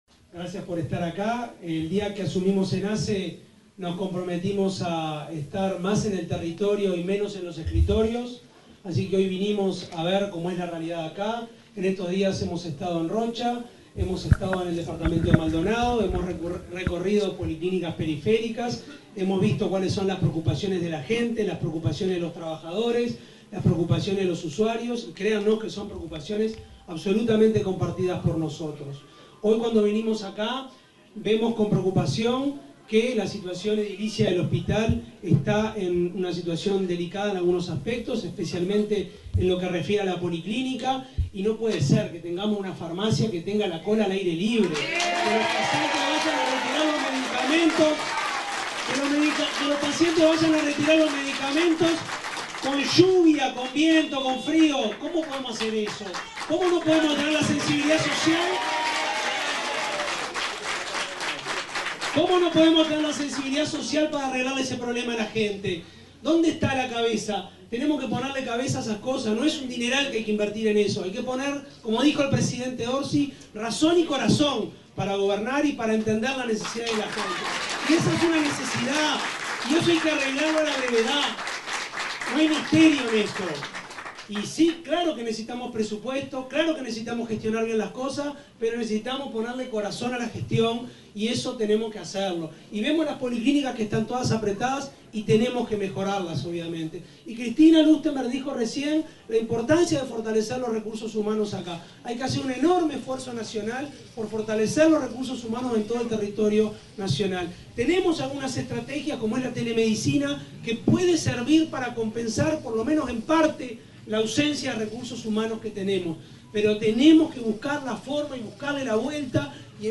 Palabras del presidente de ASSE, Álvaro Danza
Palabras del presidente de ASSE, Álvaro Danza 02/05/2025 Compartir Facebook X Copiar enlace WhatsApp LinkedIn El presidente de la Administración de los Servicios de Salud del Estado (ASSE), Álvaro Danza, participó, este viernes 2 en el centro auxiliar del Chuy, departamento de Rocha, en el lanzamiento de la Comisión Binacional Asesora de Frontera.